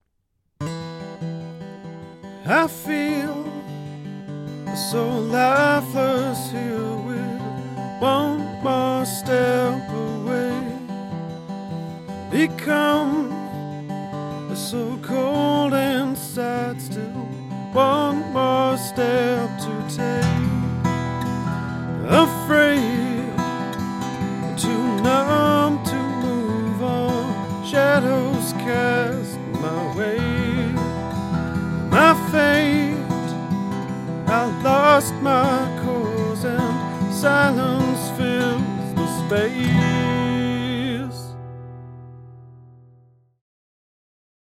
Audiobeispiele: Singer-Songwriter aufnehmen
Akustikgitarre aufnehmen